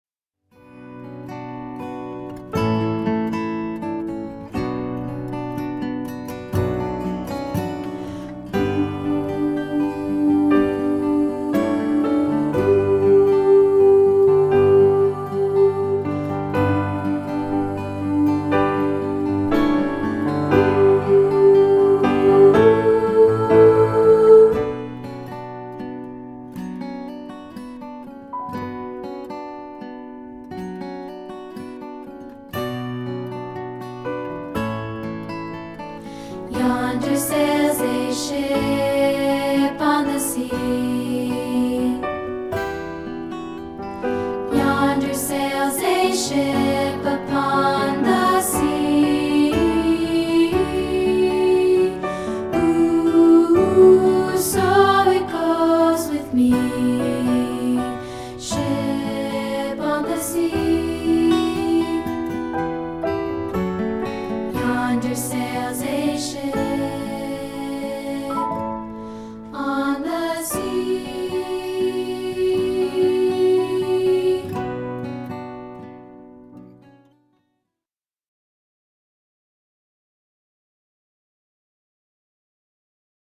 This audio track is part 3 isolated.